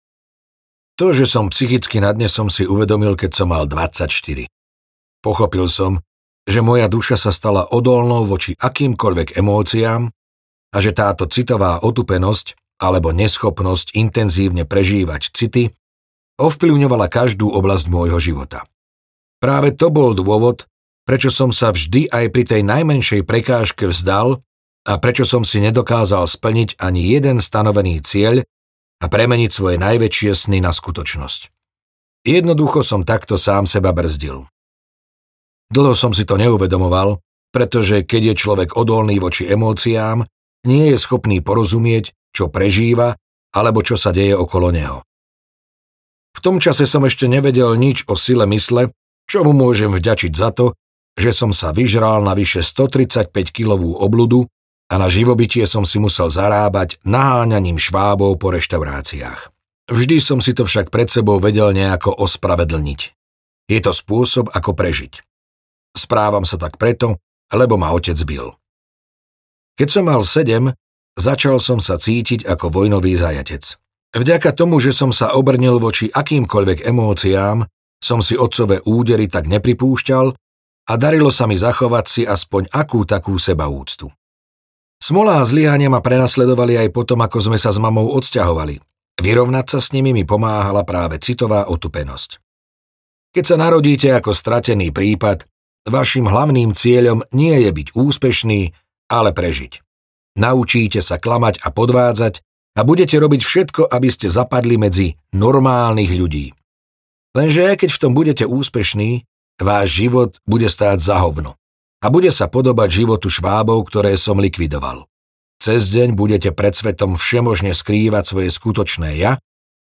Nikdy sa nezastavím audiokniha
Ukázka z knihy
nikdy-sa-nezastavim-audiokniha